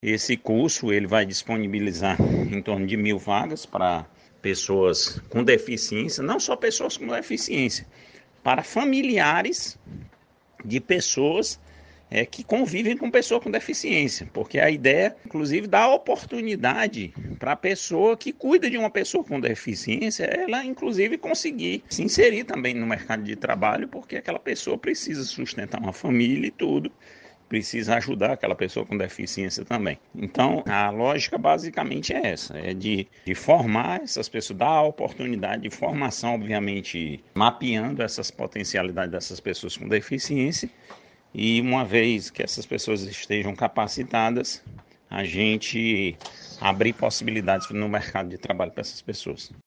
Sobre a parceria fala o secretário executivo de Políticas em Saúde da Sesa, Marcos Gadelha.